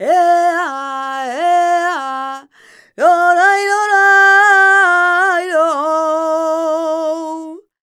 46b18voc-fm.aif